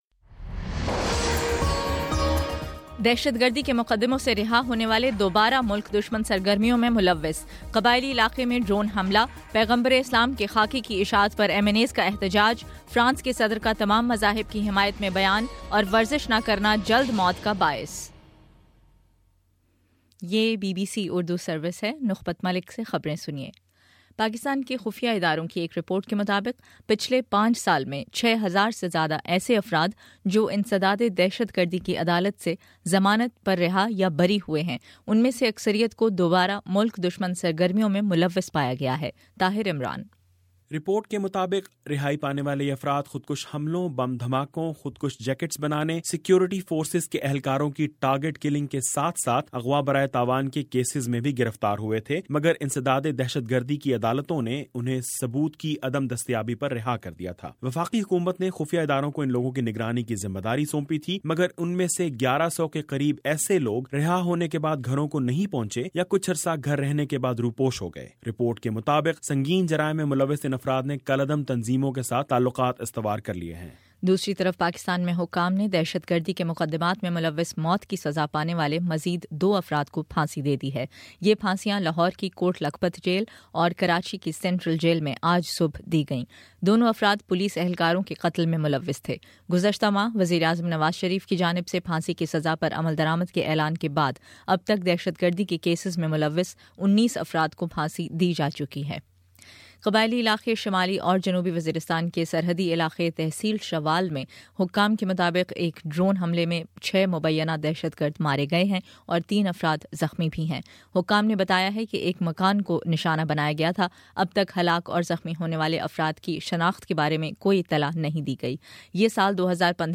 جنوری 15: شام چھ بجے کا نیوز بُلیٹن